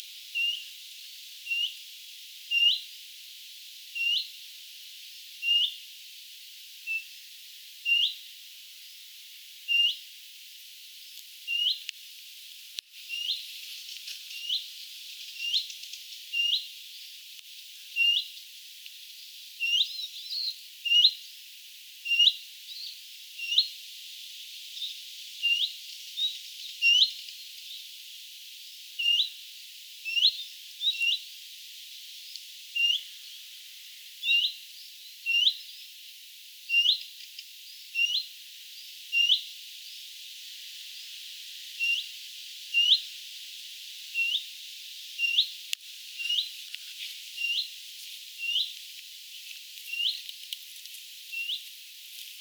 pajulintuemo huomioääntelee,
joitakin poikasen vienoja pieniä ääniä
pajulintuemo_aika_lahella_poikasta_poikasen_hentoja_aania.mp3